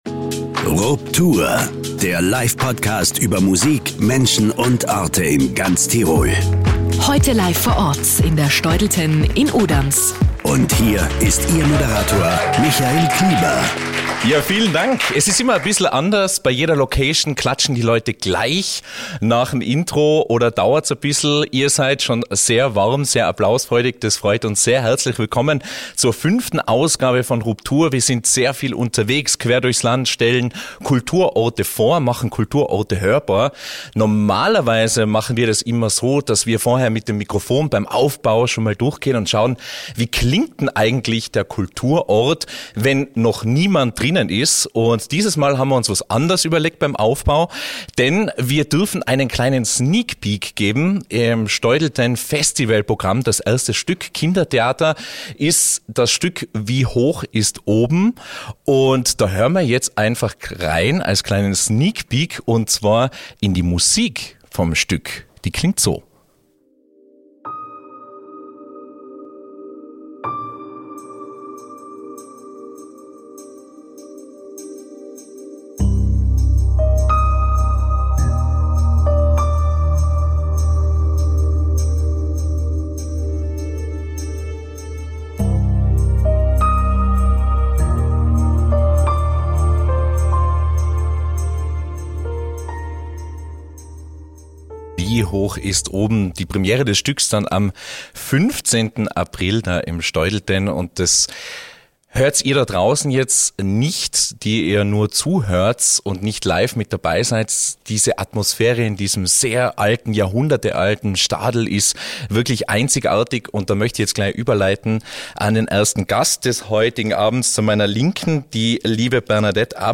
Tirols Livepodcast über Musik, Menschen und Orte zu Gast in der Steudltenn Uderns.